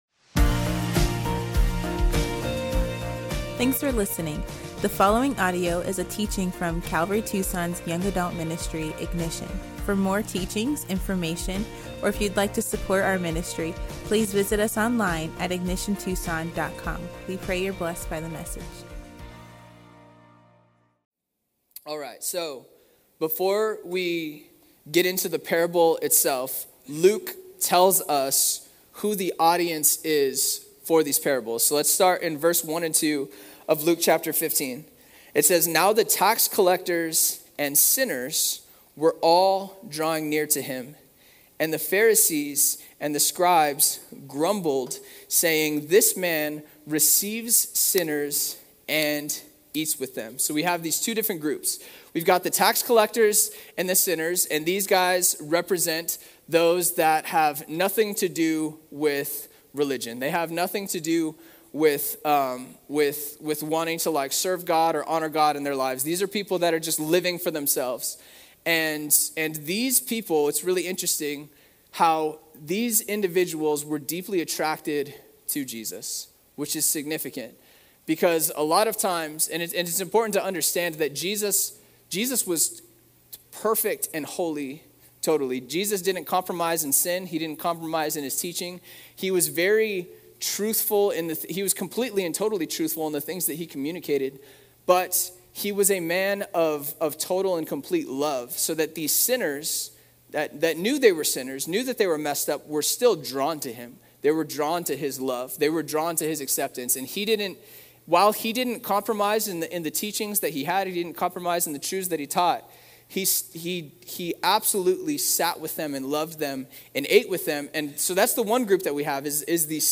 Luke 15" at Ignition Young Adults on May 30